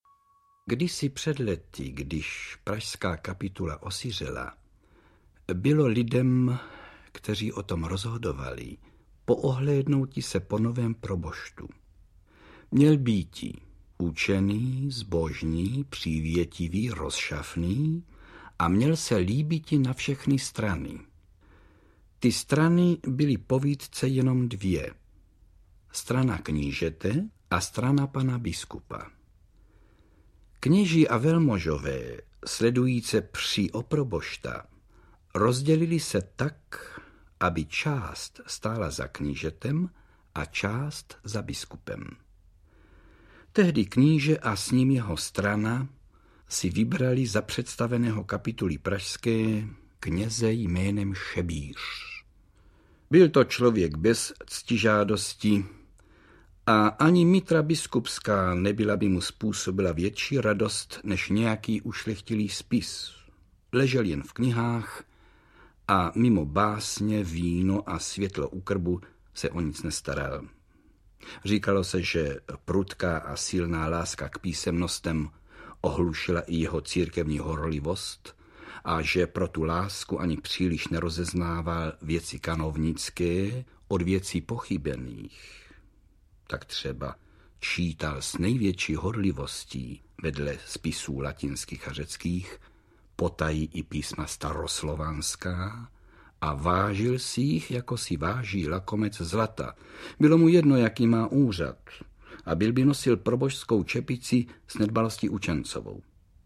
Umělecký přednes - Karel Höger.
Ukázka z knihy
• InterpretKarel Höger